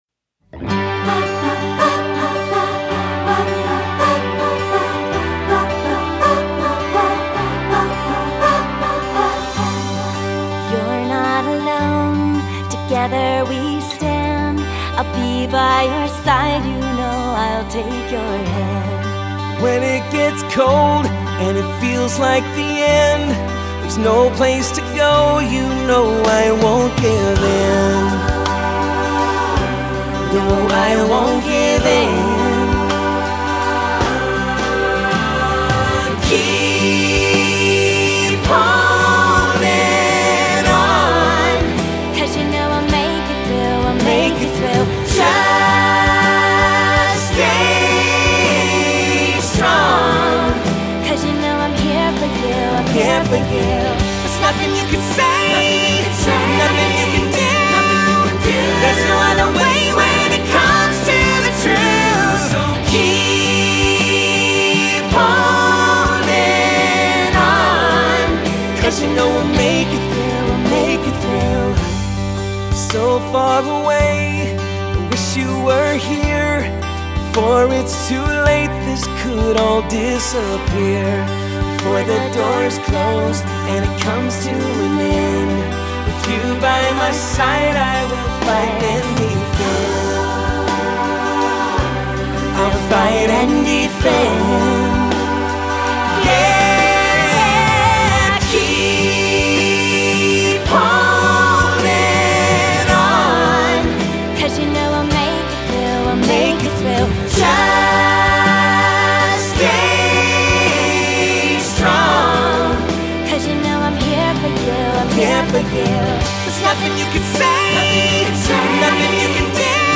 Voicing: SAB